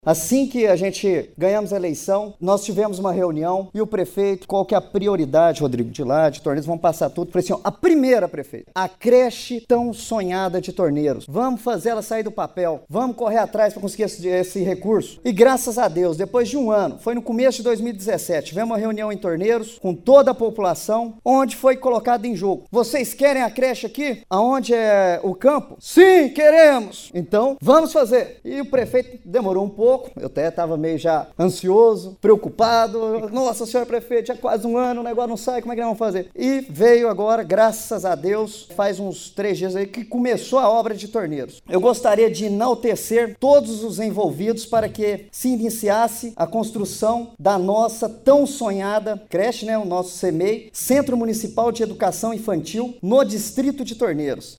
O vereador Rodrigo Alves Meneses (MDB) fez uso tribuna livre durante reunião Ordinária da Câmara Municipal. Ele aproveitou para relembrar da luta pela benfeitoria no distrito e comemorou o início das obras: